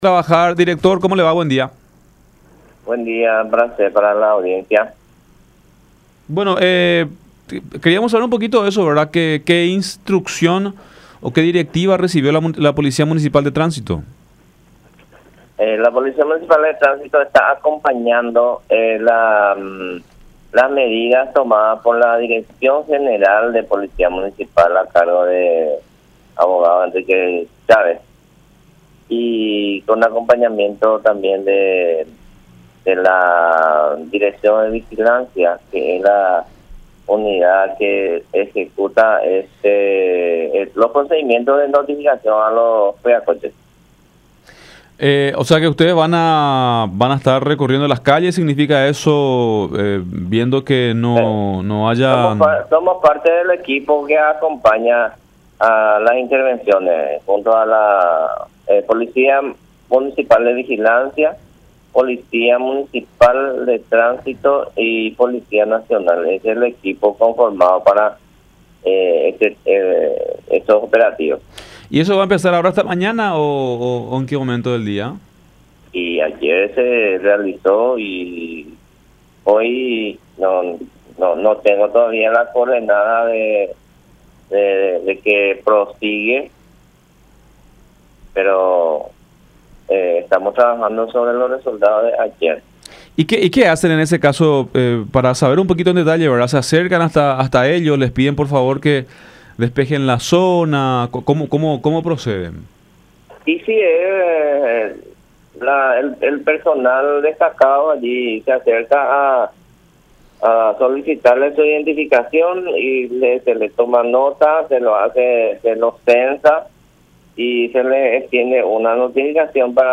“Nosotros estamos acompañando las medidas que ha tomado la dirección general. Somos parte del equipo que acompaña las intervenciones en este tipo de casos”, dijo Blas Sosa, director de la PMT de Asunción, en diálogo con La Unión.